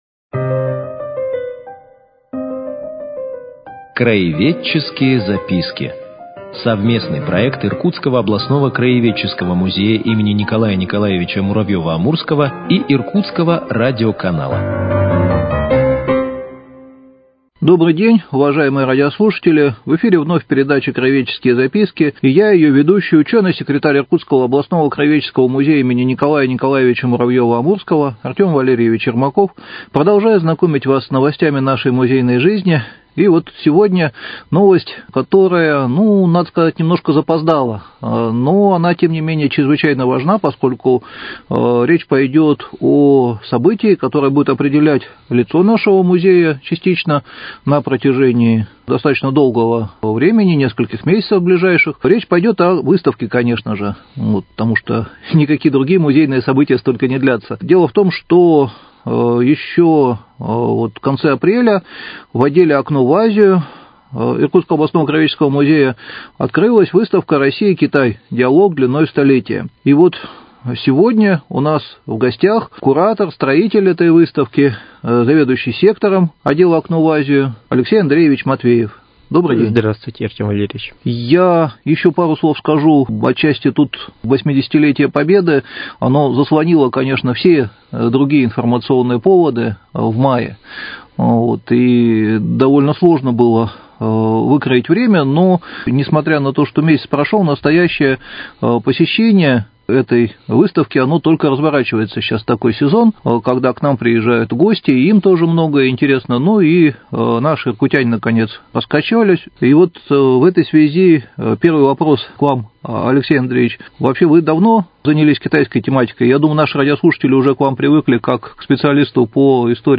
Цикл передач – совместный проект Иркутского радио и Иркутского областного краеведческого музея им. Н.Н.Муравьёва - Амурского.